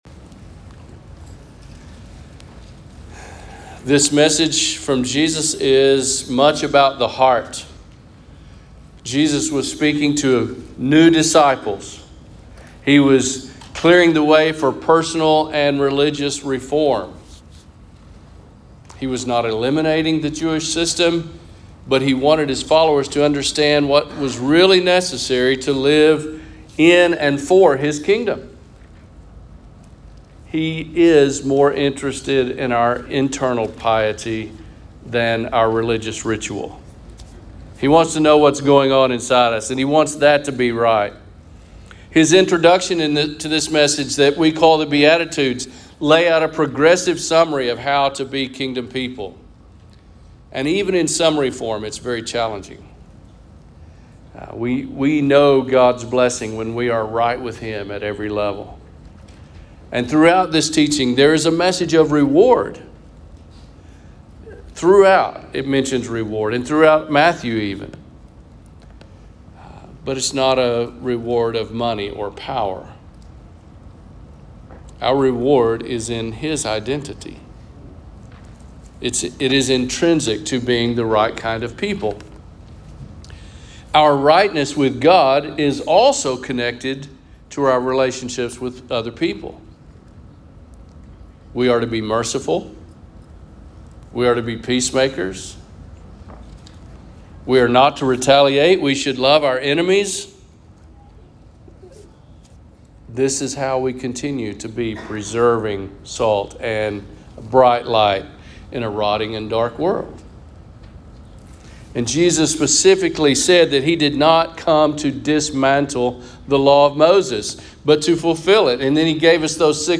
Sermons | Lawn Baptist Church